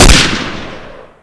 m1carbine_shoot.wav